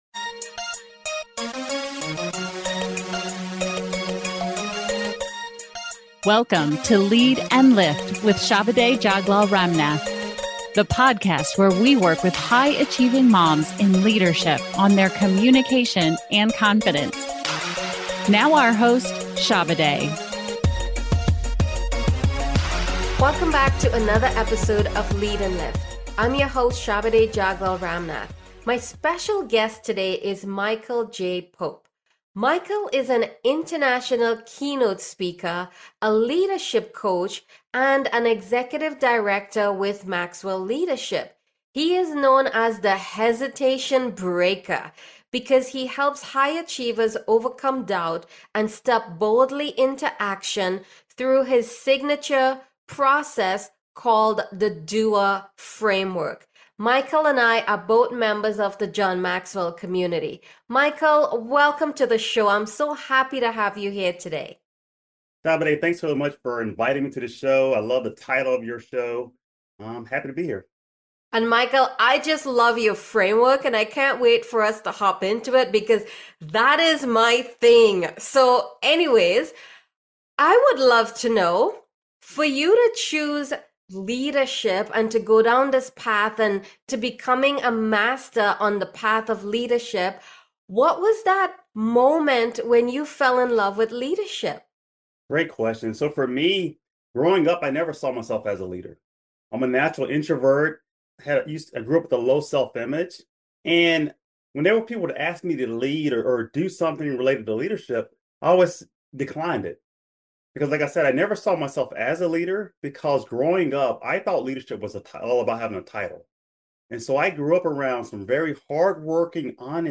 as she interviews expert guests who are leading in business and life and lifting others along the way!